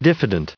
added pronounciation and merriam webster audio
221_diffident.ogg